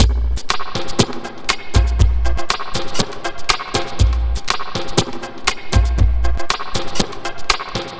Astro 4 Drumz Wet.wav